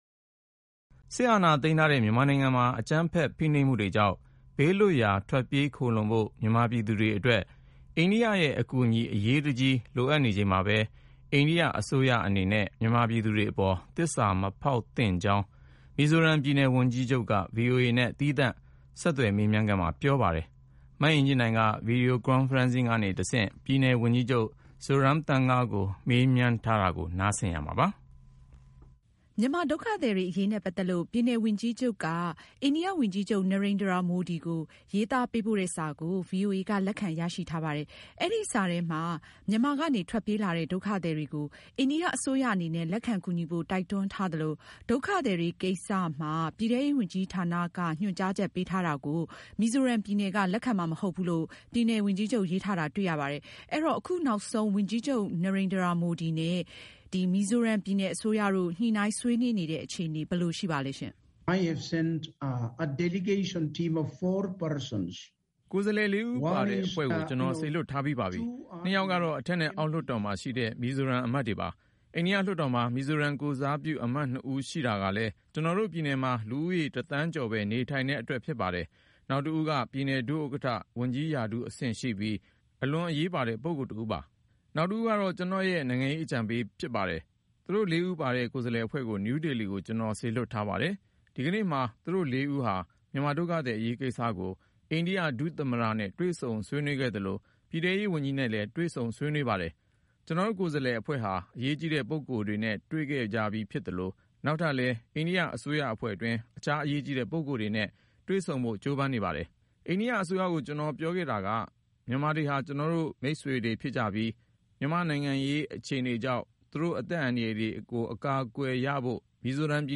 တိမ်းရှောင်လာတဲ့ မြန်မာတွေကို လက်ခံရေး မီဇိုရမ် ဝန်ကြီးချုပ်နဲ့ ဆက်သွယ်မေးမြန်းချက်